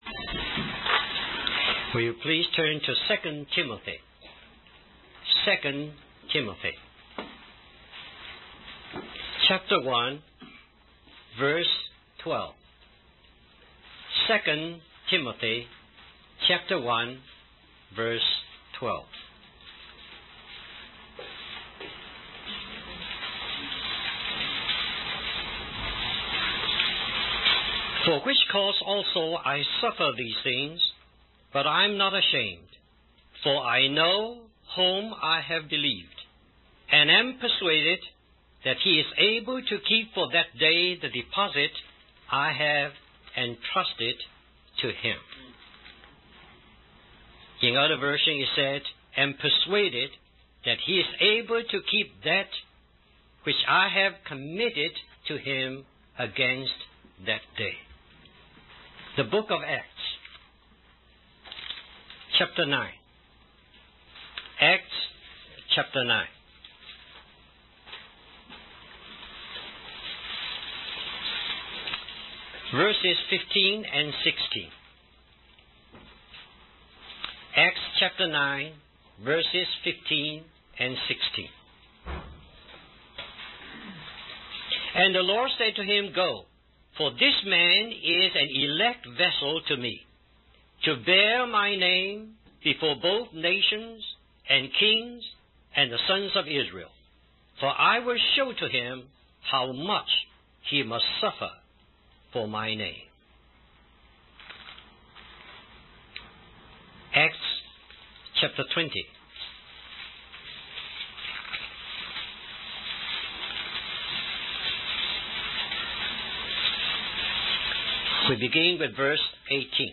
He encourages the audience to know God fully and commit themselves to him wholeheartedly, as Paul did.